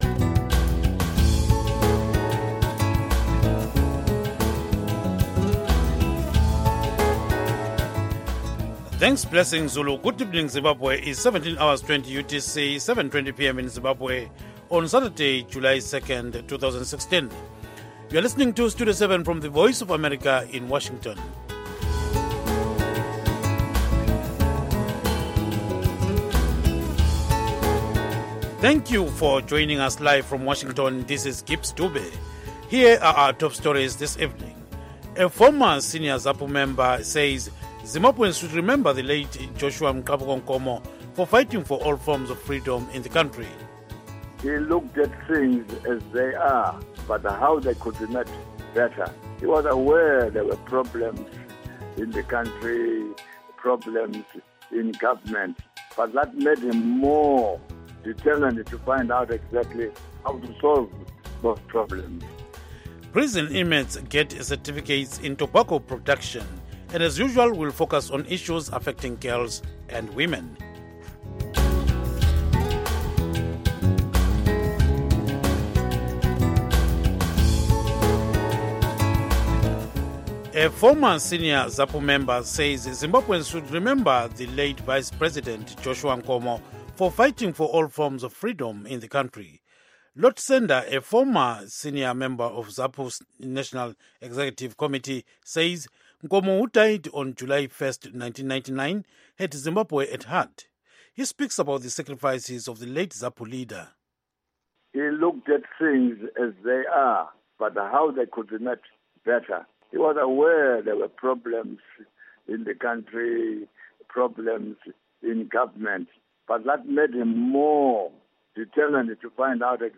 News in English